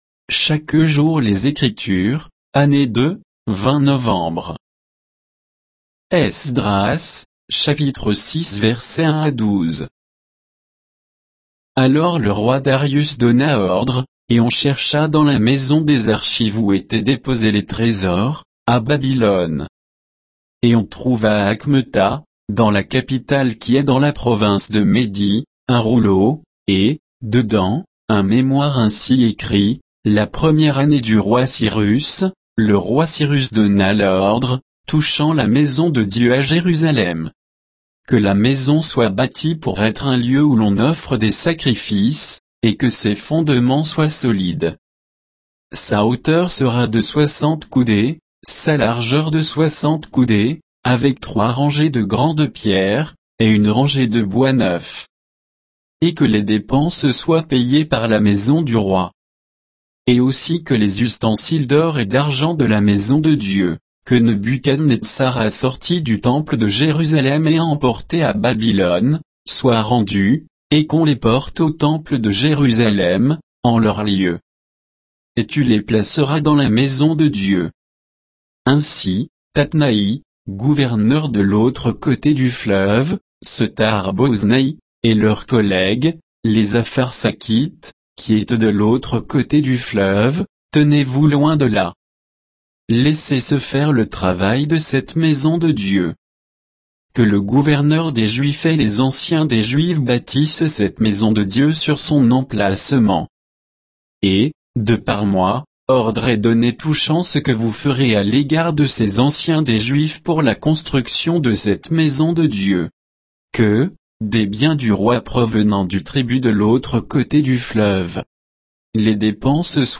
Méditation quoditienne de Chaque jour les Écritures sur Esdras 6, 1 à 12